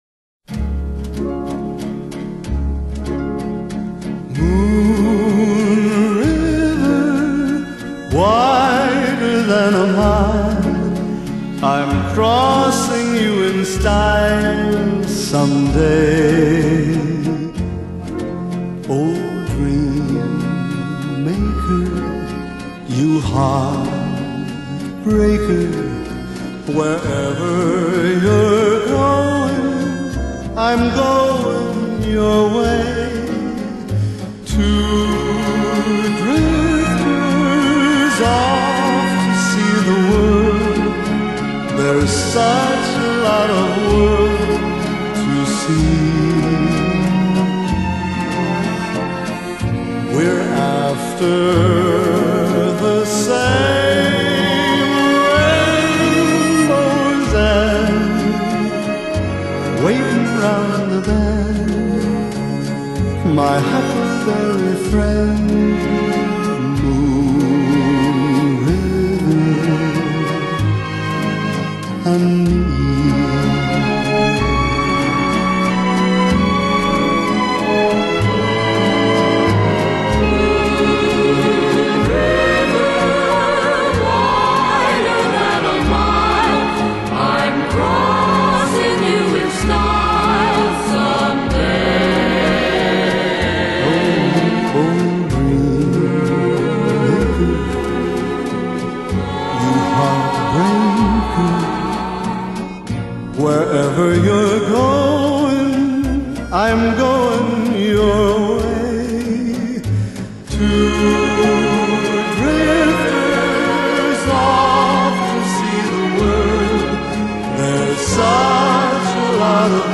Genre: Pop, Ballads, Easy Listening, Oldies